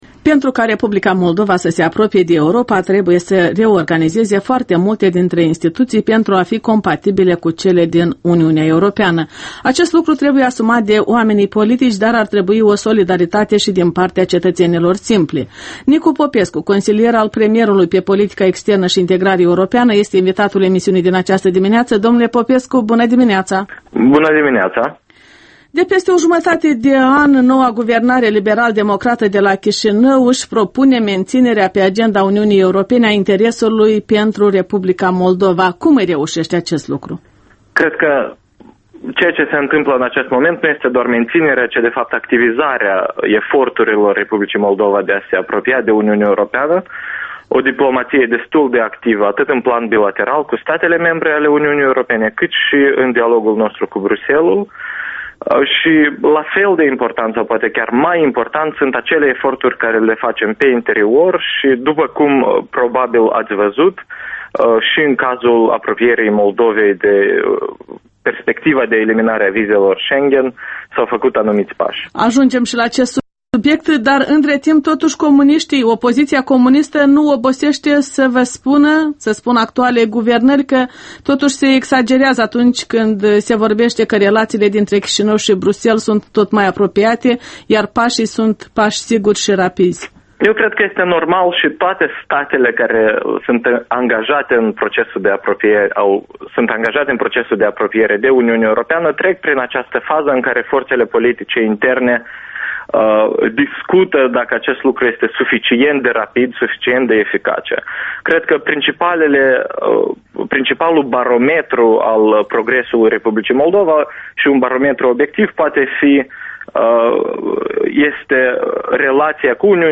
(AUDIO)Interviu cu Nicu Popescu: Agenda de europenizare a R. Moldova. Radio Europa Liberă. 04.03.2010.